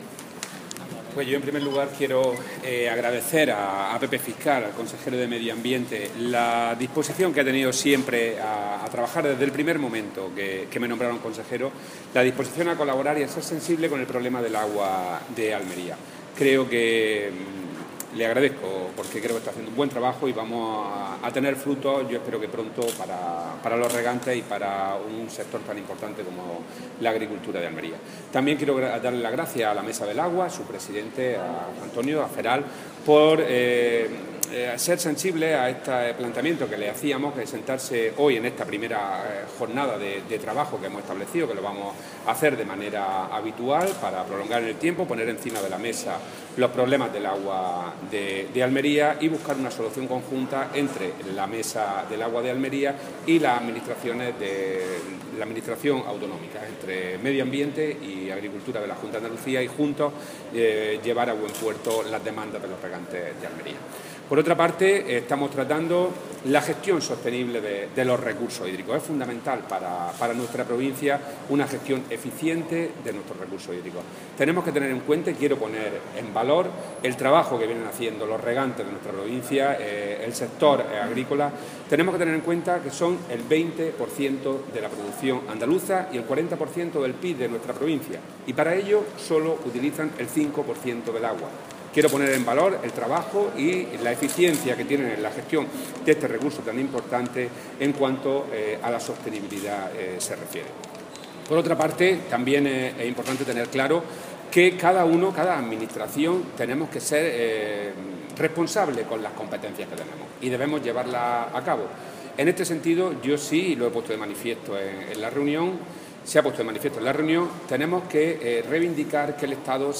Declaraciones del consejero de Agricultura, Pesca y Desarrollo Rural, Rodrigo Sánchez Haro.